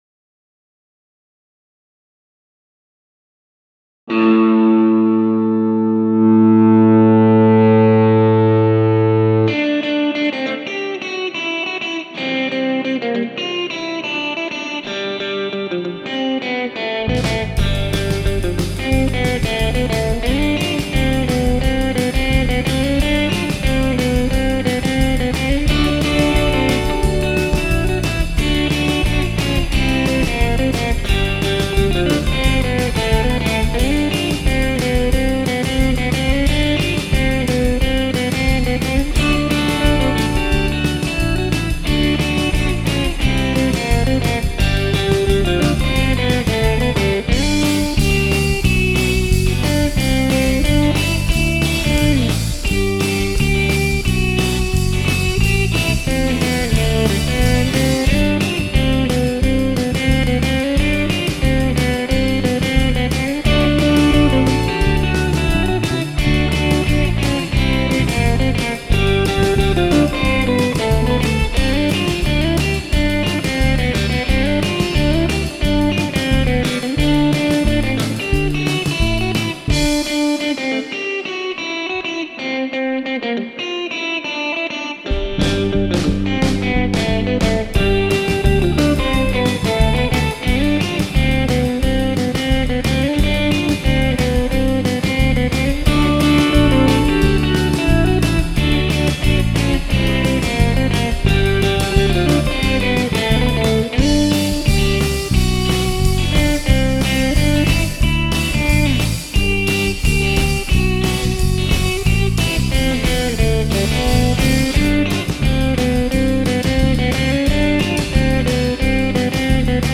done as a an instrumental
Using my Jazz-O-Caster guitar.
Jazzmaster pickups, switch was in middle position
Sounds great !
So I downloaded the drum tracks and bass track for this tune
Then I did all the guitar parts